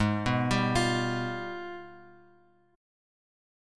AbmM13 Chord
Listen to AbmM13 strummed